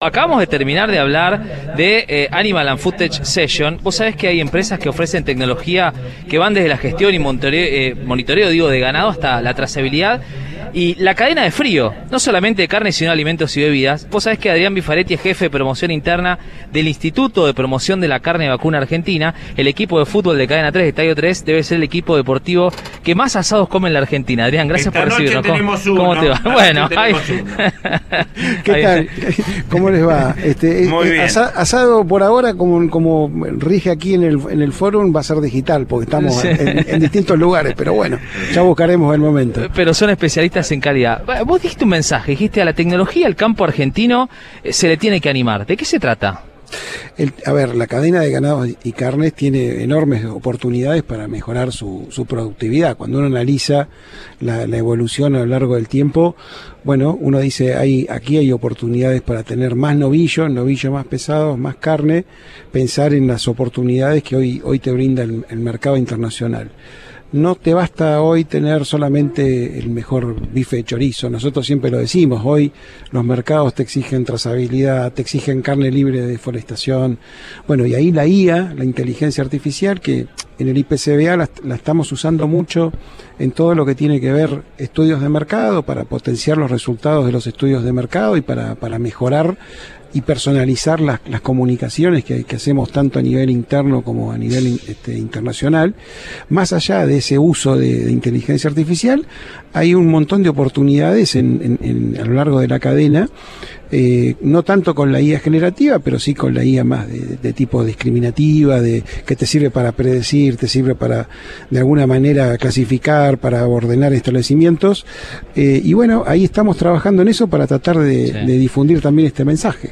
dialogó con Cadena 3 Rosario y destacó que la IA ya no es una promesa lejana